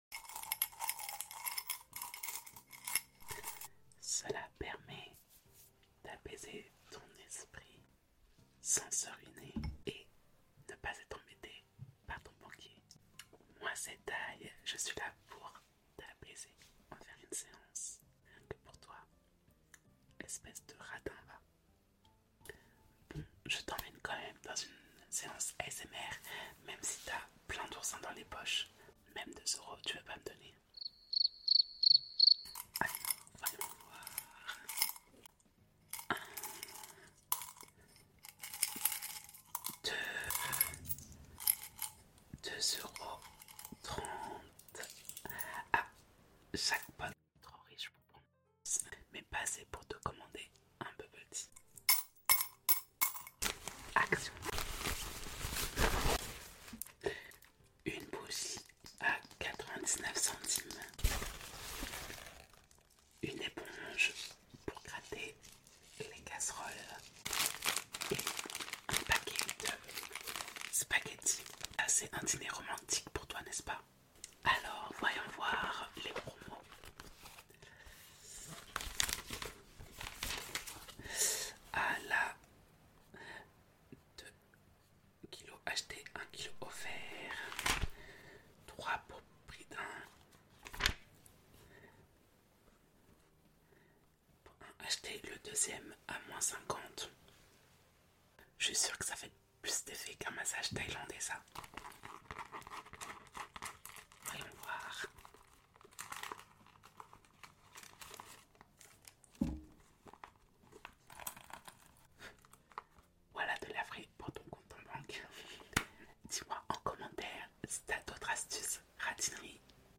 🌸 ASMR POUR LES RADINS sound effects free download